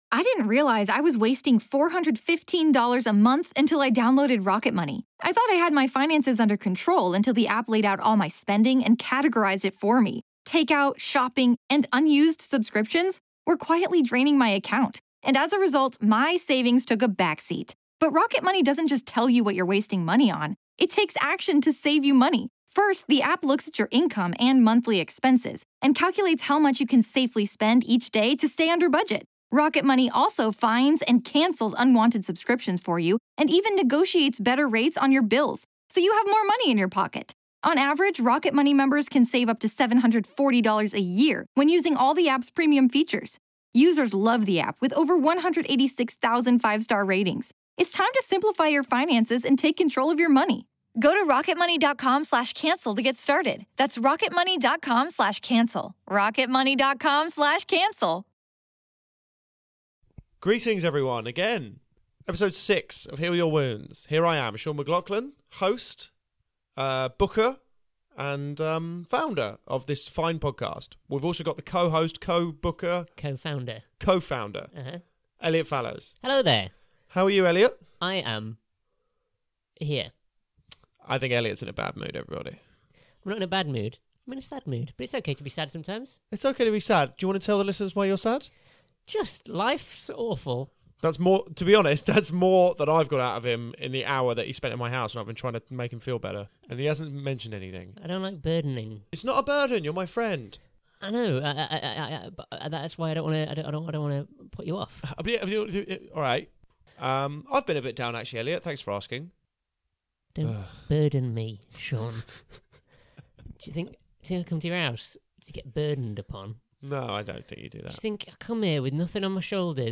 Please note the guest in this episode is exceptionally Scottish.